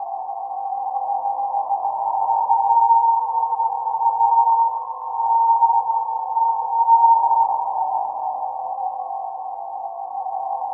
Index of /90_sSampleCDs/Spectrasonic Distorted Reality 2/Partition D/07 SCI-FI 1